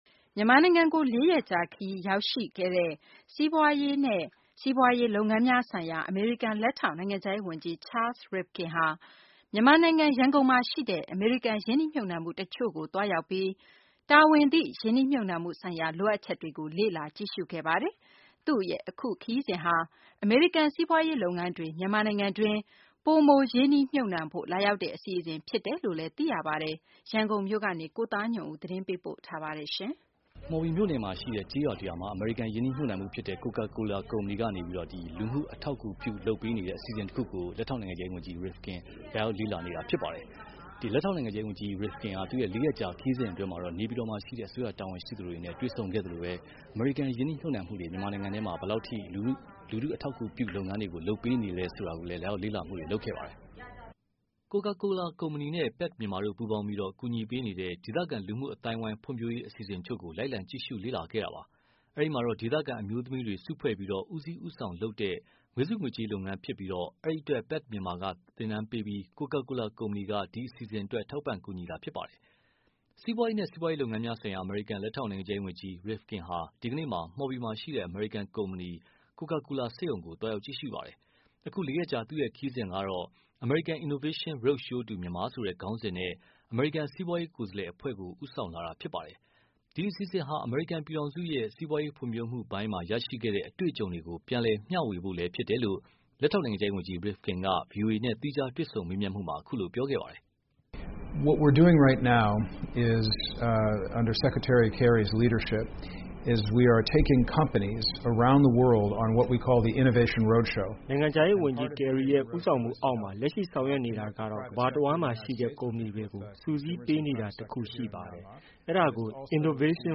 စီးပွားရေးနဲ့ စီးပွားရေးလုပ်ငန်းများဆိုင်ရာ အမေရိကန်လက်ထောက်နိုင်ငံခြားရေးဝန်ကြီး Rivkin ဟာ ဒီကနေ့မှာပဲ မှော်ဘီမှာရှိတဲ့ အမေရိကန် ကုမ္ပဏီ Coca Cola စက်ရုံကိုလည်း သွားရောက် ကြည့်ရှုပါတယ်။ အခု လေးရက်ကြာ သူ့ရဲ့ခရီးစဉ်ဟာ American Innovation Roadshow to Myanmar ဆိုတဲ့ ခေါင်းစဉ်နဲ့ အမေရိကန်စီးပွားရေးကိုယ်စားလှယ်အဖွဲ့ကိုပါ ဦးဆောင်လာတာ ဖြစ်ပါတယ်။ ဒီ အစီအစဉ်ဟာ အမေရိကန်ပြည်ထောင်စုရဲ့ စီးပွားရေးဖွံဖြိုးမှုပိုင်းမှာ ရခဲ့တဲ့ အတွေ့အကြုံတွေကို မျှဝေဖို့လည်းဖြစ်တယ်လို့ လက်ထောက်နိုင်ငံခြားရေးဝန်ကြီး Rivkin က ဗွီအိုအေနဲ့ သီးခြားတွေ့ဆုံမေးမြန်းမှုမှာ အခုလို ပြောခဲ့ပါတယ်။